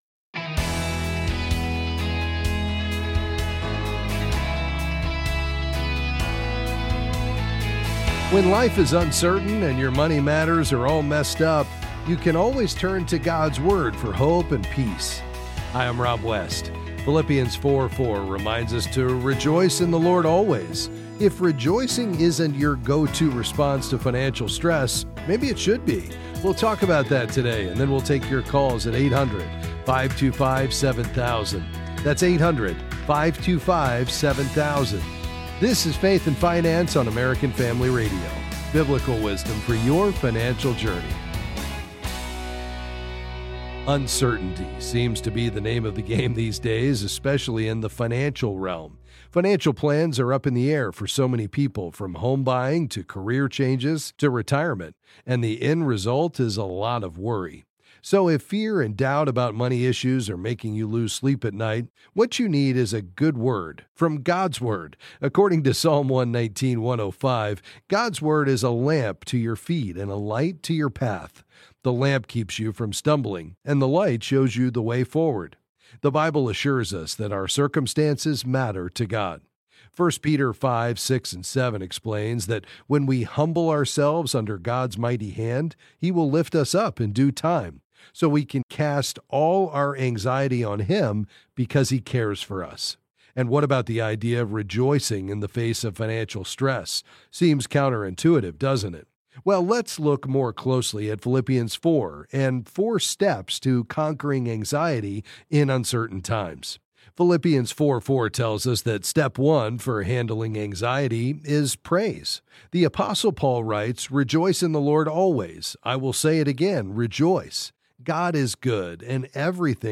Then he answers a variety of listener's financial questions.